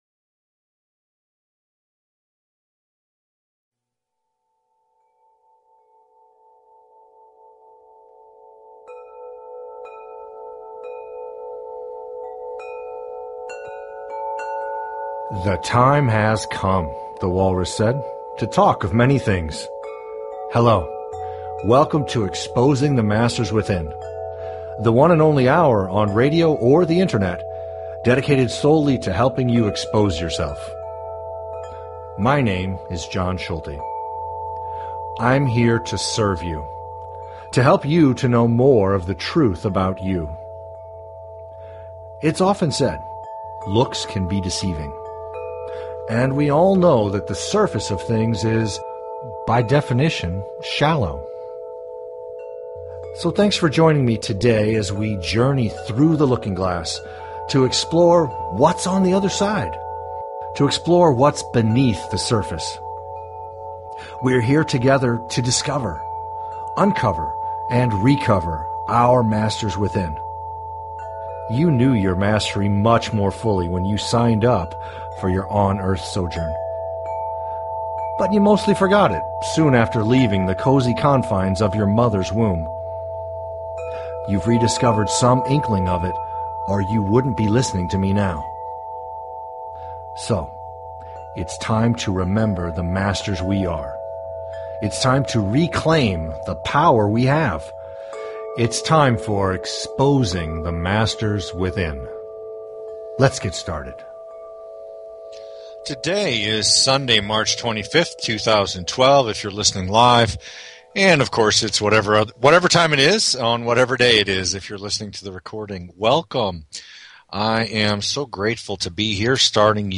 Talk Show Episode, Audio Podcast, Exposing_the_Masters_Within and Courtesy of BBS Radio on , show guests , about , categorized as